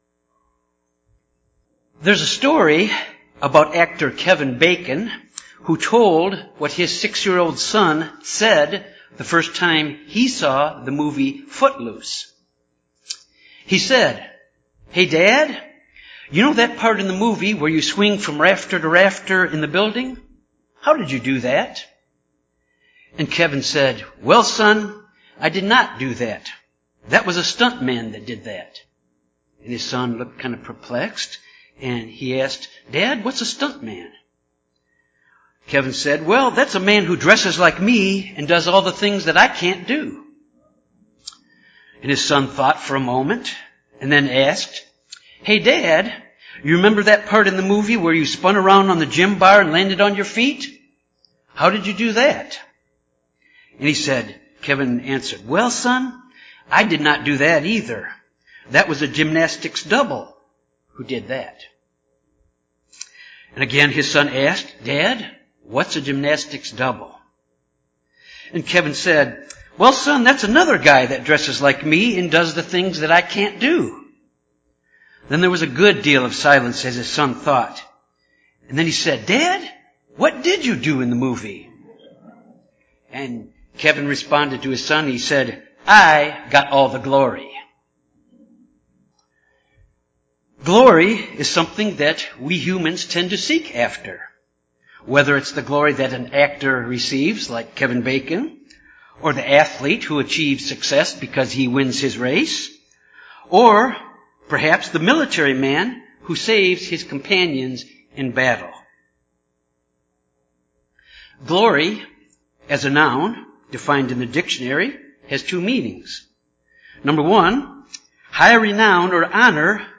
Given in Jonesboro, AR Little Rock, AR